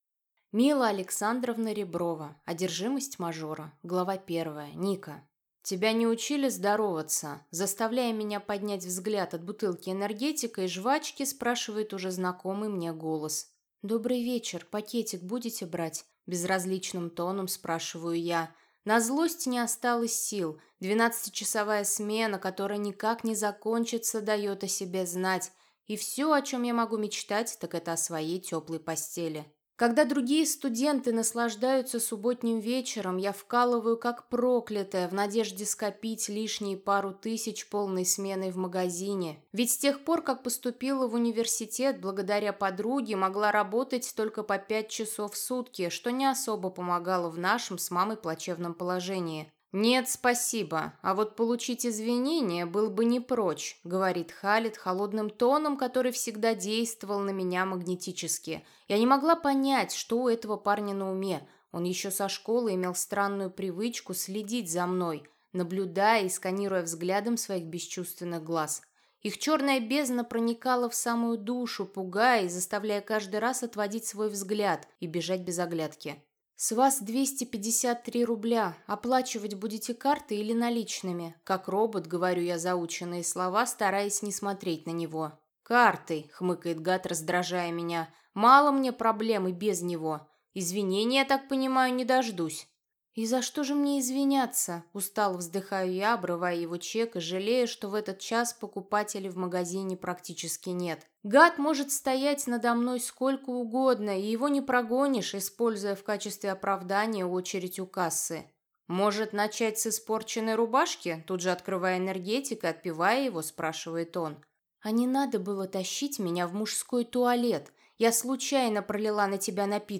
Аудиокнига Одержимость мажора | Библиотека аудиокниг